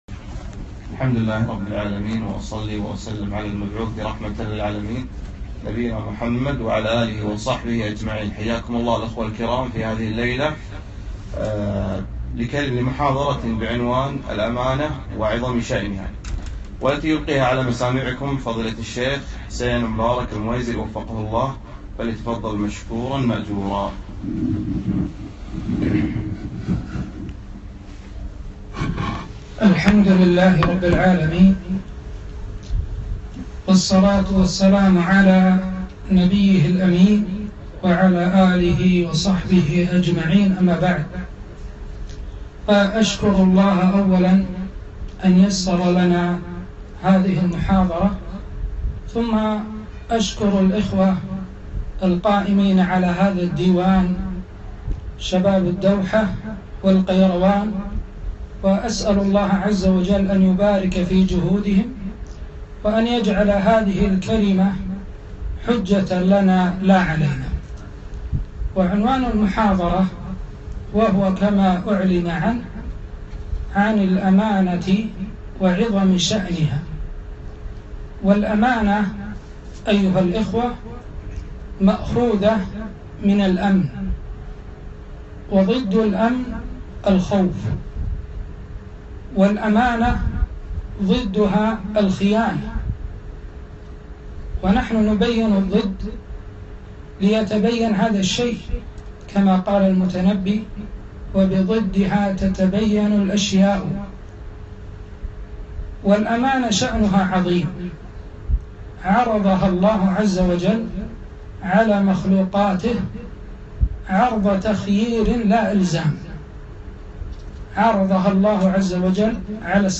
محاضرة - الأمانة وعظم شأنها